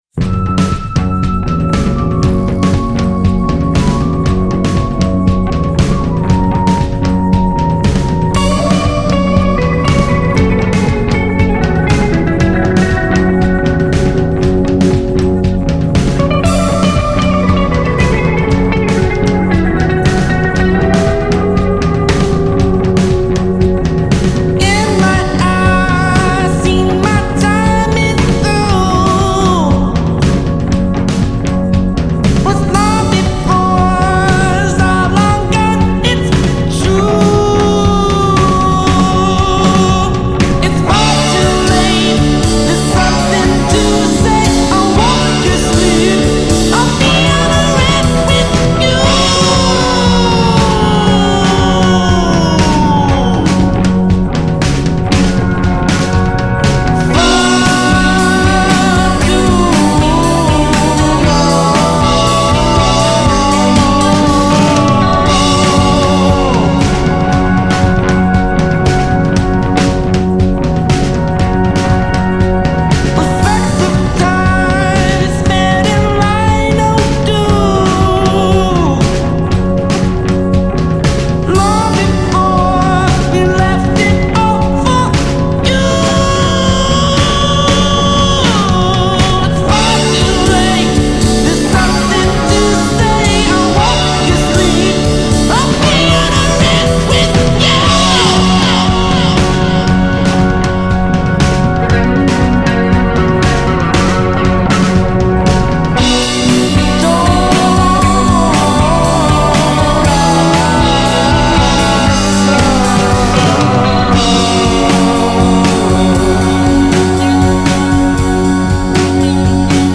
psych-rock foursome
guitar
drums
bass
keyboards